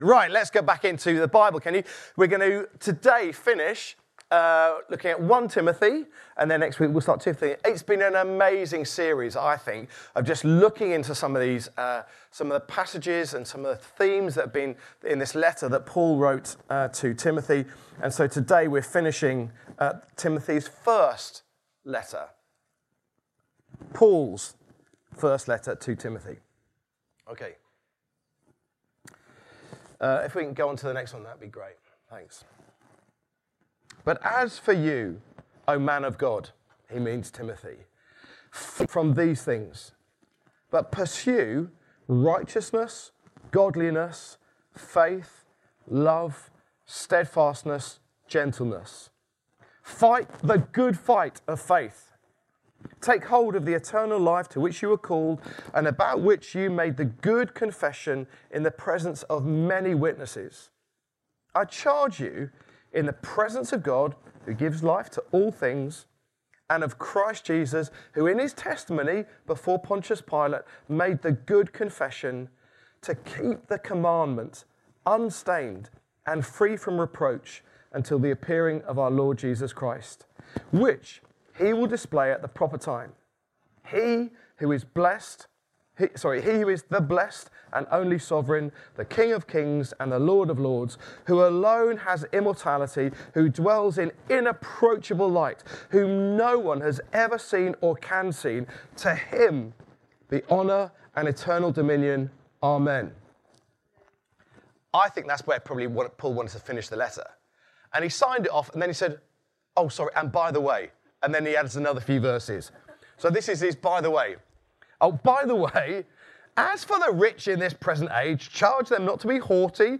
Download Final charge to Timothy | Sermons at Trinity Church